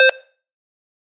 Звуки кассы
Звук сканирования штрих-кода